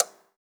clock_tick_03.wav